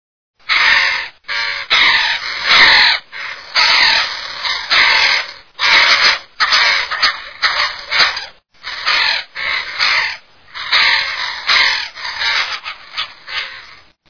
Canto de la corneja negra
cantoCornejaNegra.mp3